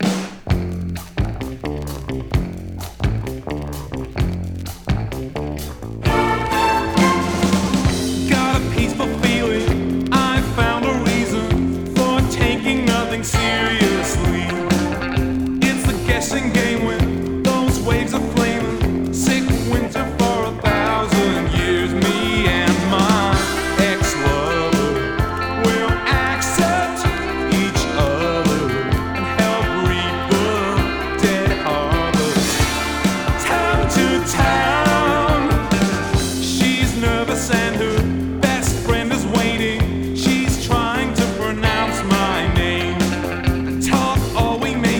粒揃いに良い曲良いメロディが並び、コーラスワークも嬉しい良盤。
Rock, Pop, Indie　France　12inchレコード　33rpm　Stereo